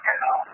EVP's From Some Very Friendly Spirits